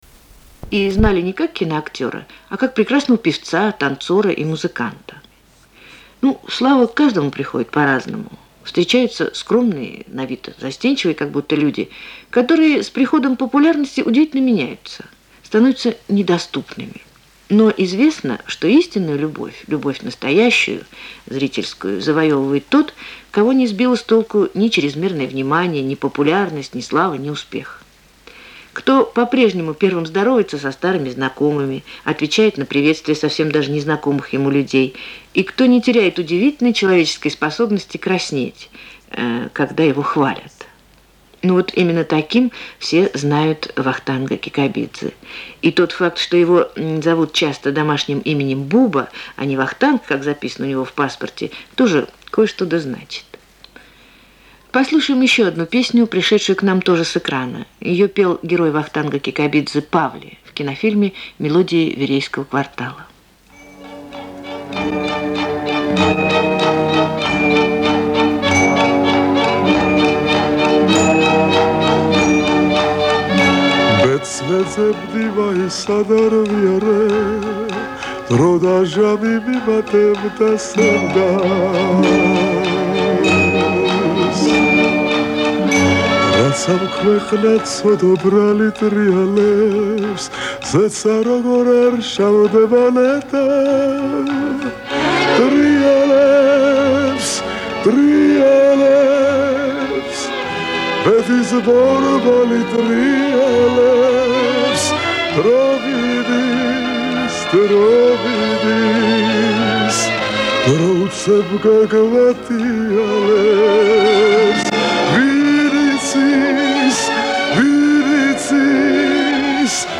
Запись на ленту с эфира была сделана летом 1980 года.